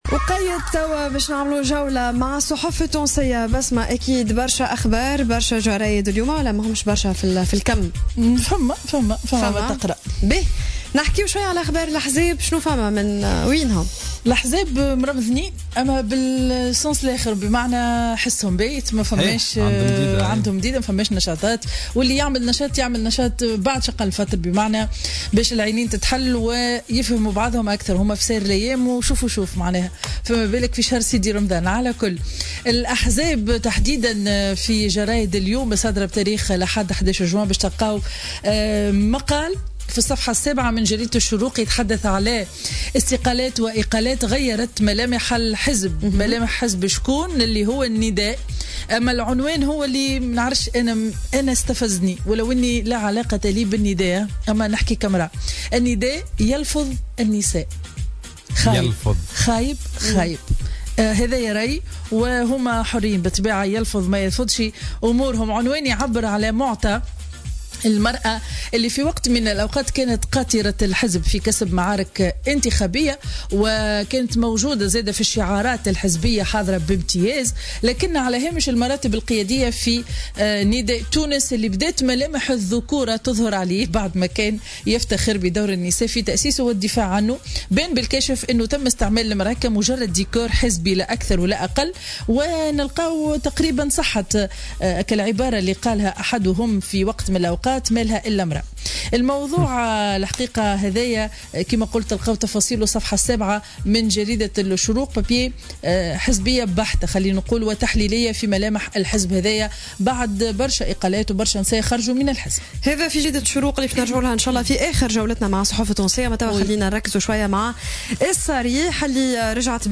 Revue de presse du dimanche 11 juin 2017